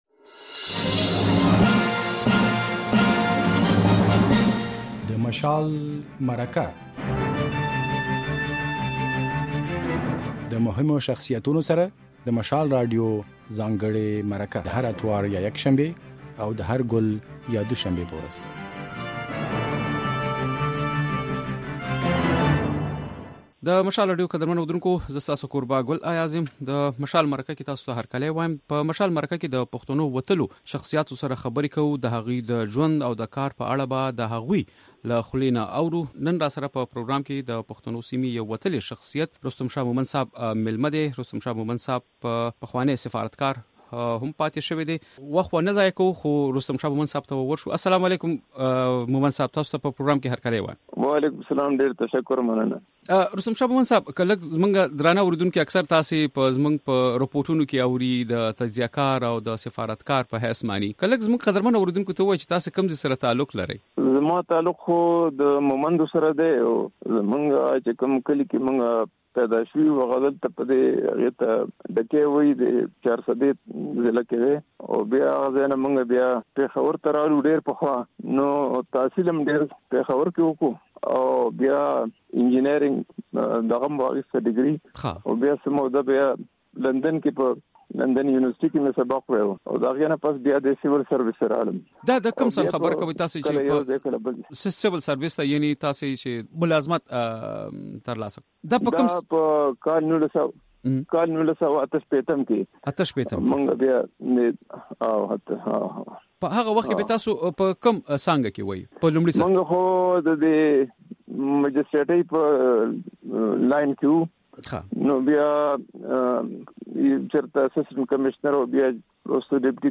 د مشال مرکه کې مو پخواني سفارتکار رستم شاه مومند مېلمه دی.